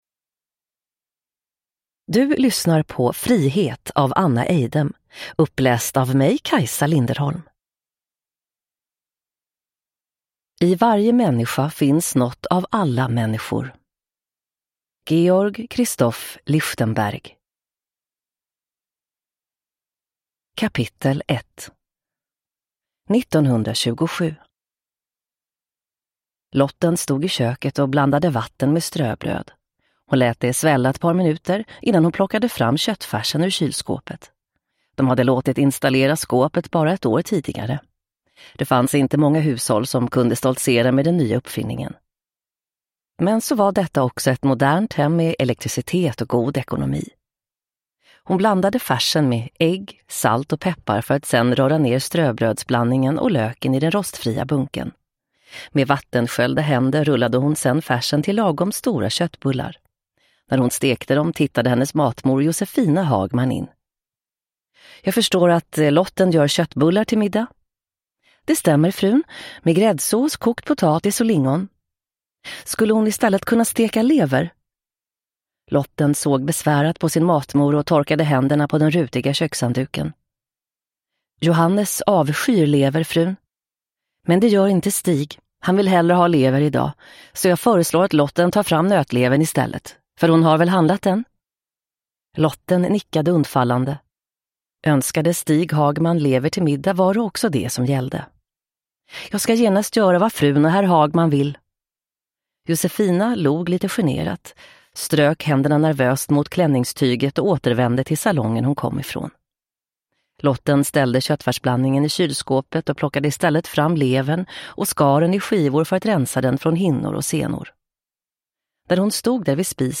Frihet (ljudbok) av Anna Eidem